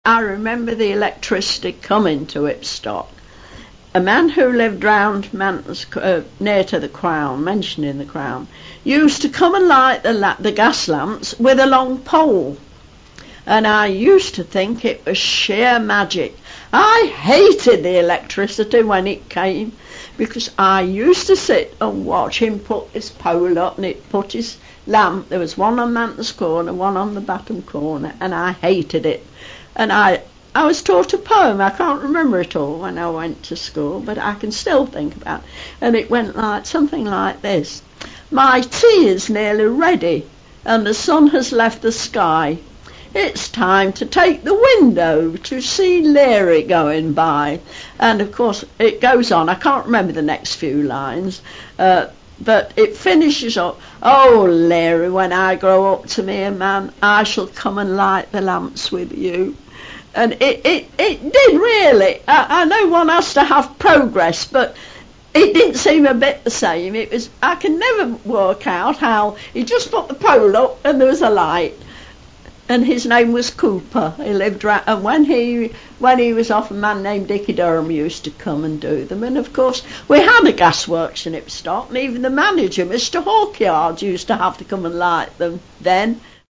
In the 2000s EMOHA added short oral history clips from the Archive to village pages on the Leicestershire Villages website.